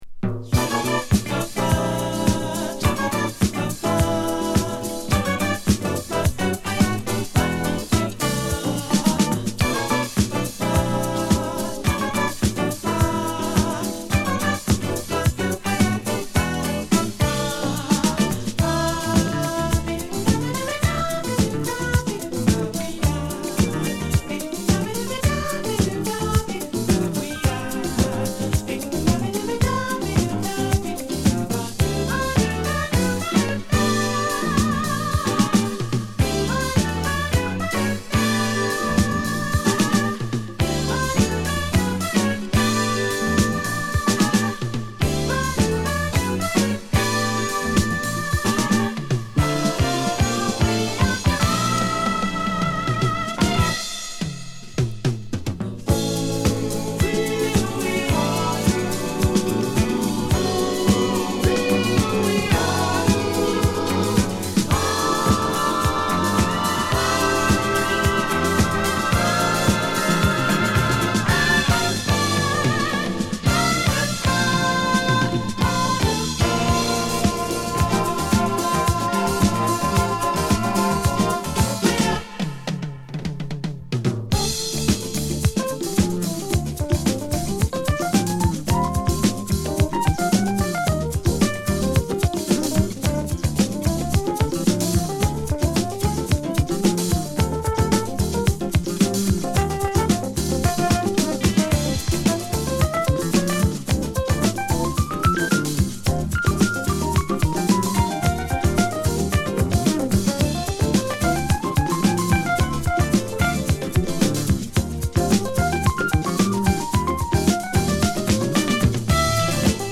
高揚するエレピのリードに女性スキャット、そしてブラジリアン・ビートのグルーヴが絡むブリージンサウンドの傑作！